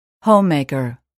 단어번호.0646 대단원 : 3 소단원 : a Chapter : 03a 직업과 사회(Work and Society)-Professions(직업) homemaker [hóummèikər] 명) (전업) 주부 mp3 파일 다운로드 (플레이어바 오른쪽 아이콘( ) 클릭하세요.)
homemaker.mp3